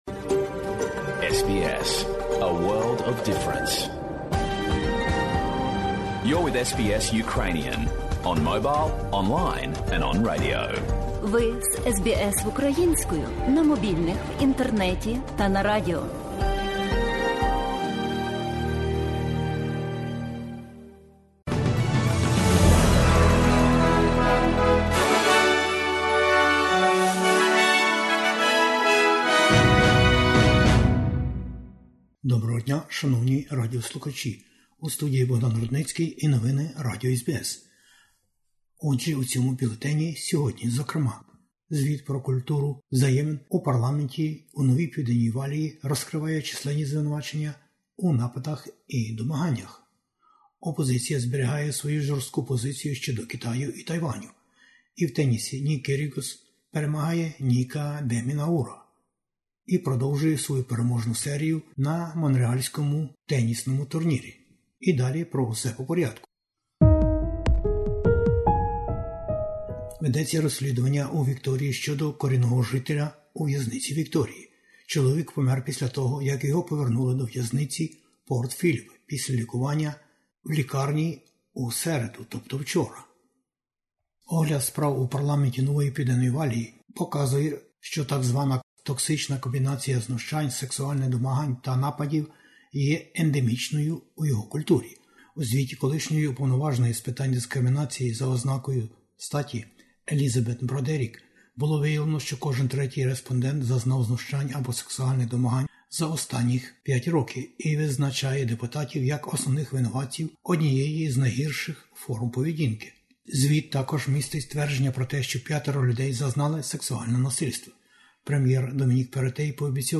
SBS News in Ukrainian - 12/08/2022